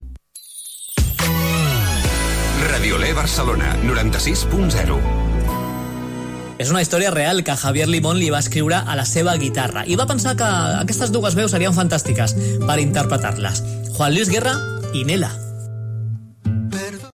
Indicatiu de l'emissora a Barcelona, presentació d'un tema musical.
FM